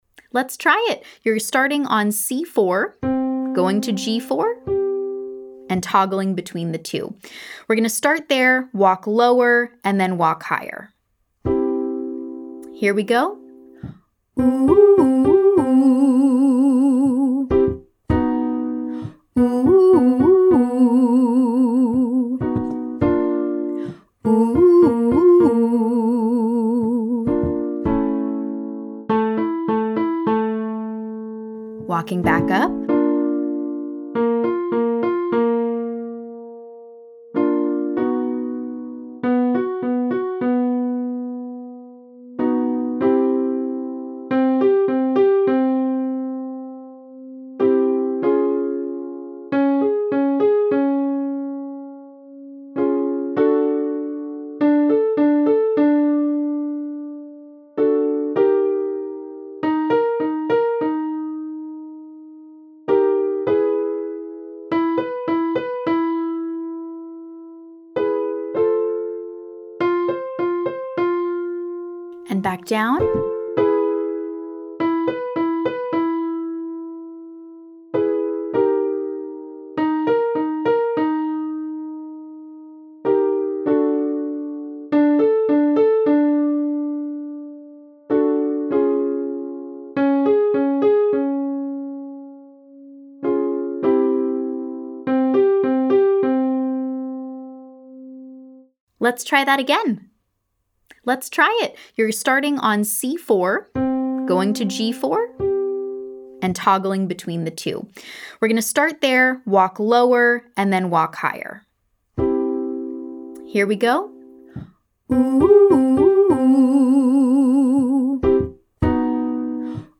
- Online Singing Lesson
Rapid 15151
Descending waterfall 8531